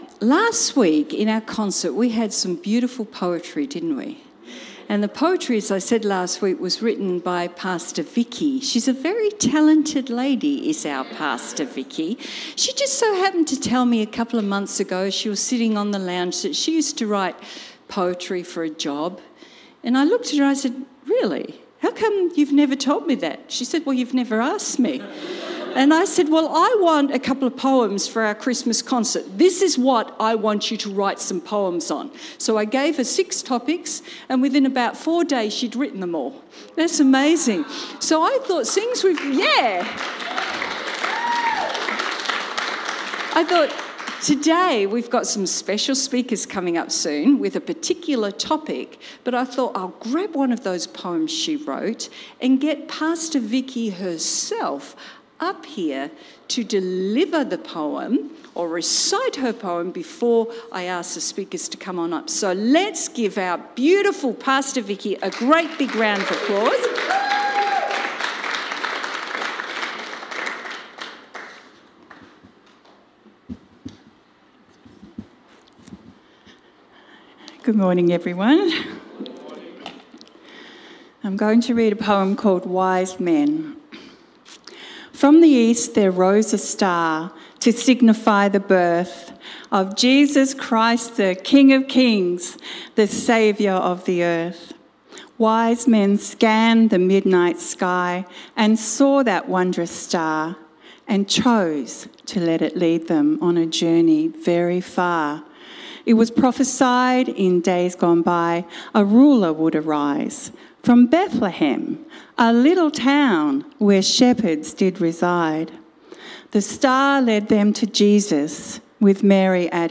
The-Wise-Men-and-their-Gifts-_-Tag-Team-Sermon.mp3